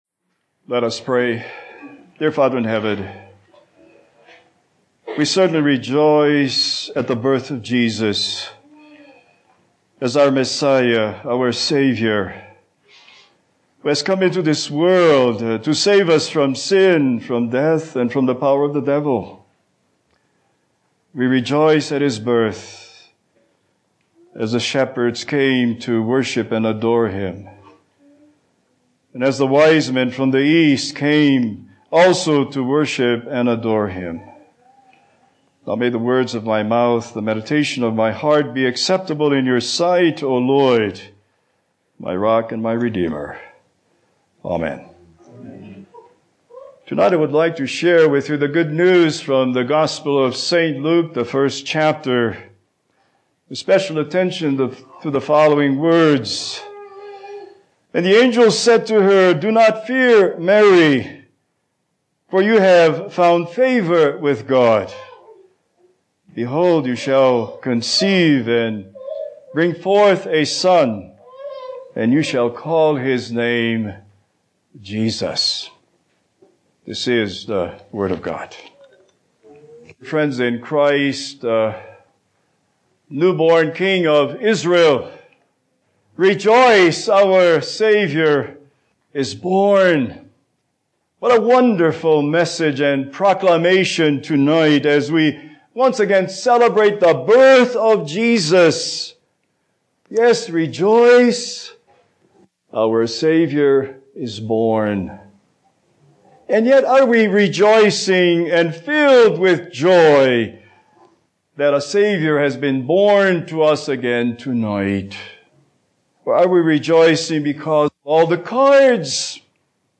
Holiday Sermons Passage: Luke 1:26-38 Service Type: Christmas Eve